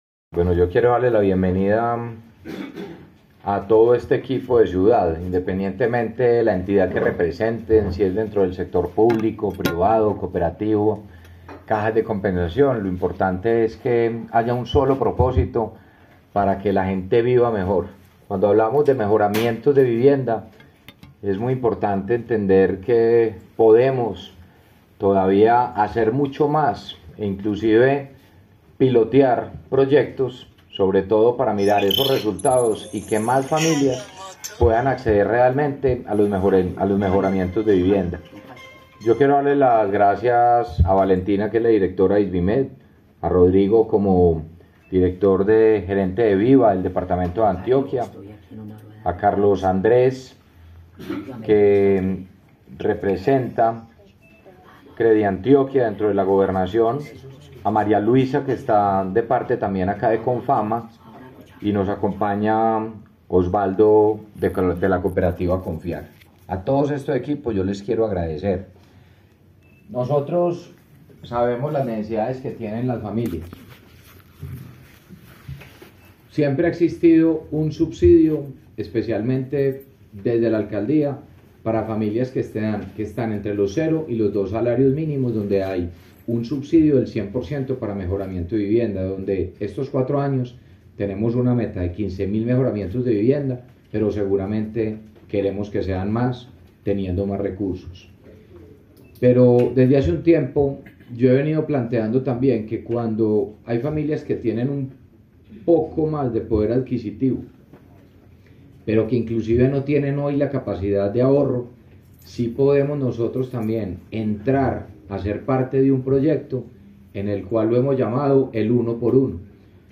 Declaraciones-alcalde-de-Medellin-Federico-Gutierrez-2.mp3